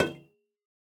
Minecraft Version Minecraft Version 1.21.5 Latest Release | Latest Snapshot 1.21.5 / assets / minecraft / sounds / block / copper_bulb / place2.ogg Compare With Compare With Latest Release | Latest Snapshot